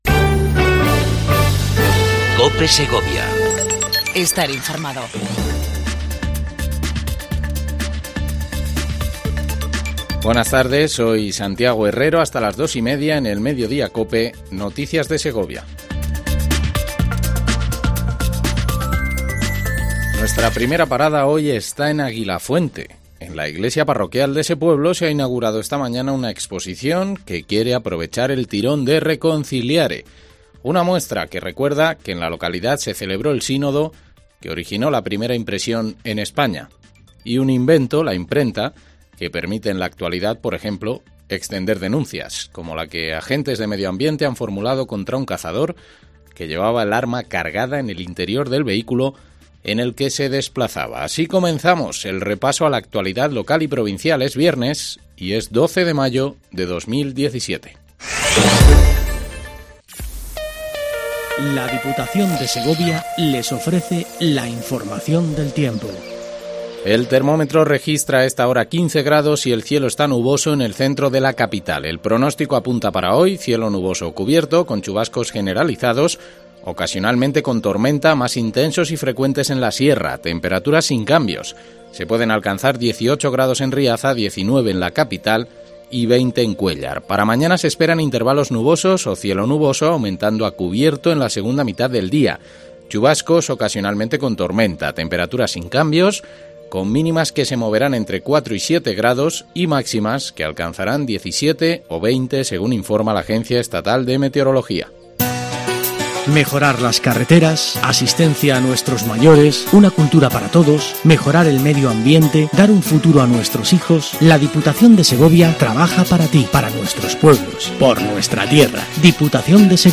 INFORMATIVO MEDIODIA COPE EN SEGOVIA 12 05 17